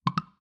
screenshot_notif.wav